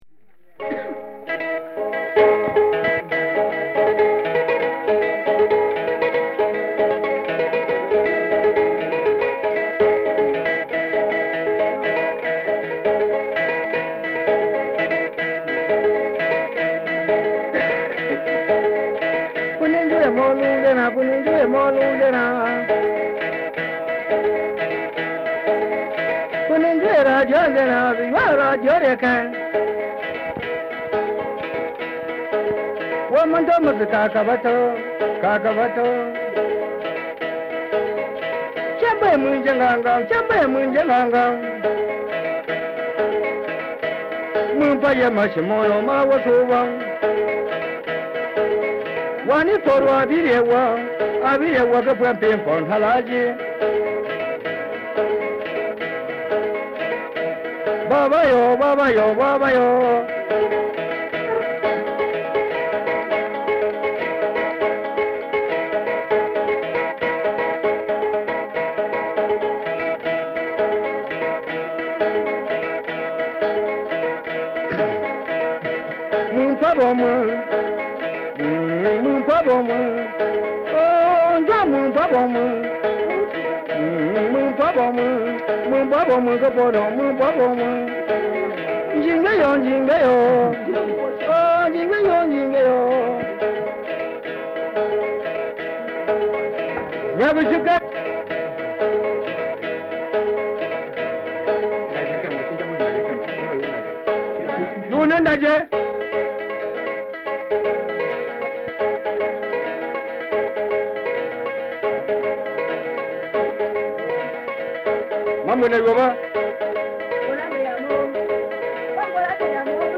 Bamum zither player